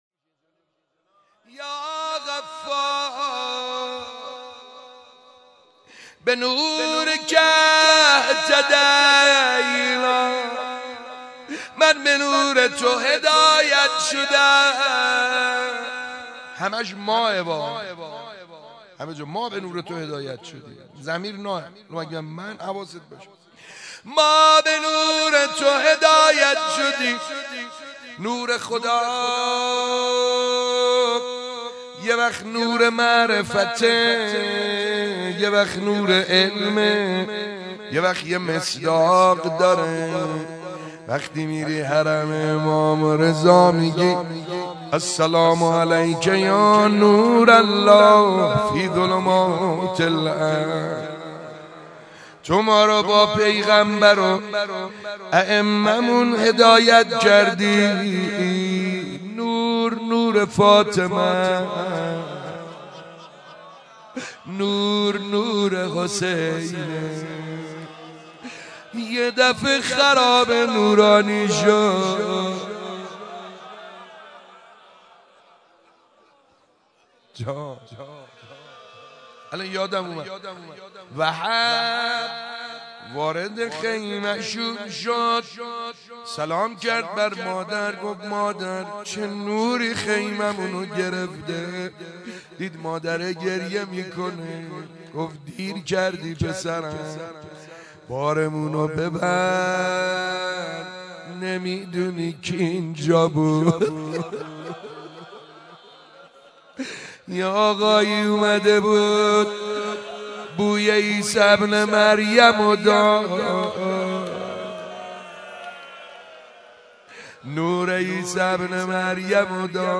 شب یازدهم ماه رمضان - مهدیه امام حسن مجتبی(ع) - تیر 94
مناجات با خدا- شب یازدهم ماه رمضان93- مهدیه امام حسن مجتبی 2.mp3